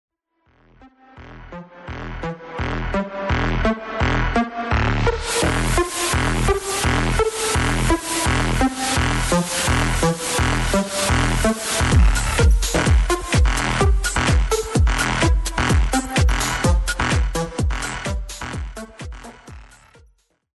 minimal stuff...